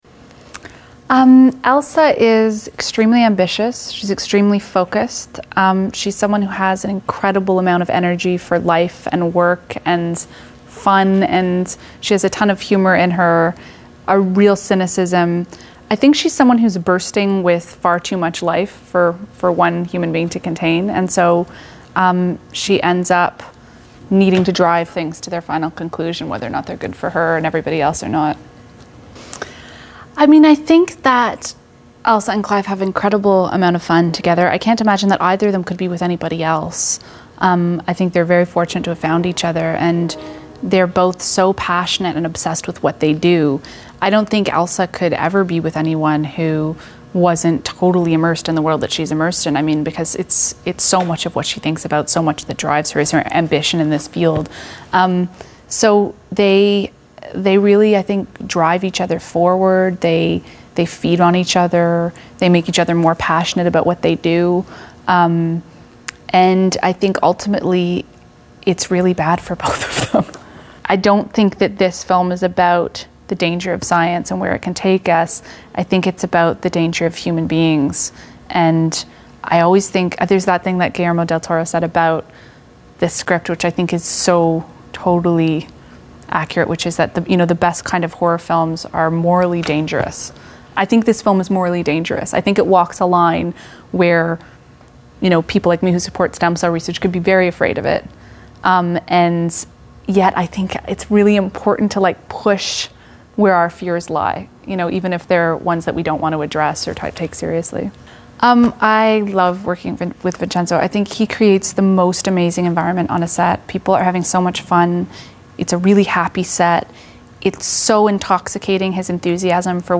Sarah Polley Interview